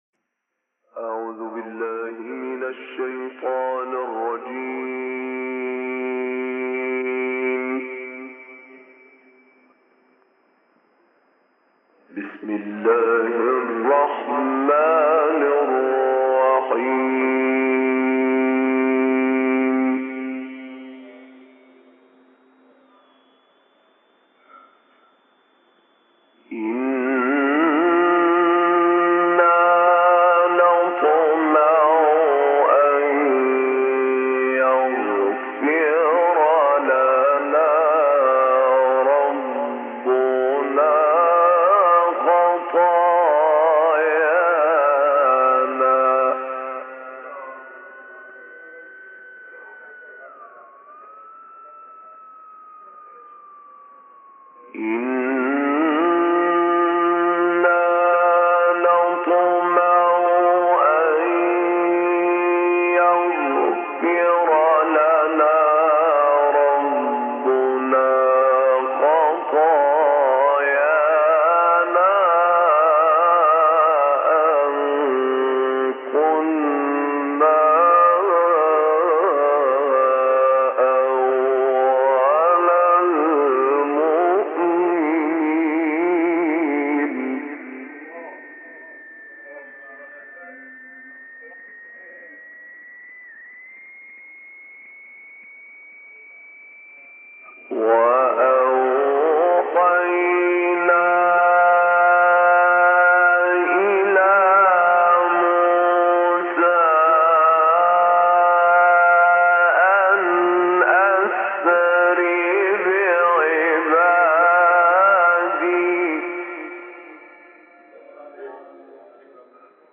تلاوت سوره شعرا استاد طاروطی | نغمات قرآن | دانلود تلاوت قرآن